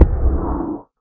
elder_hit2.ogg